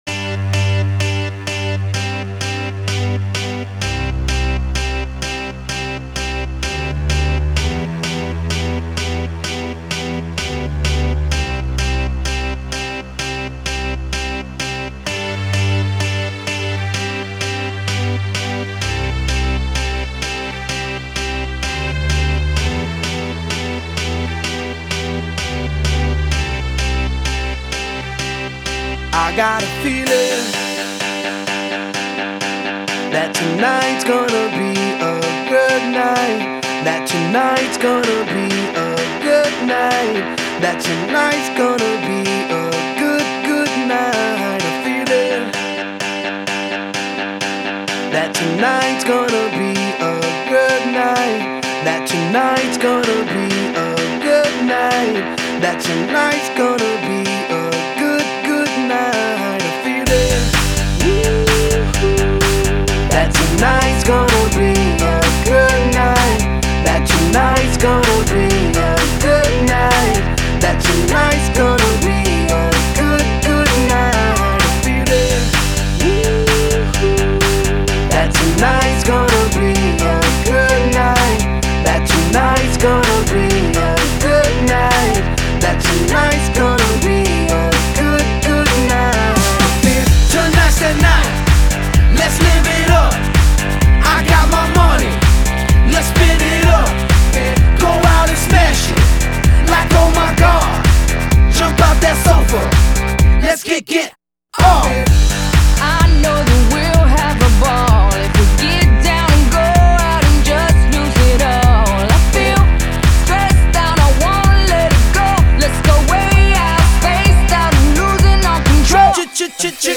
Электроника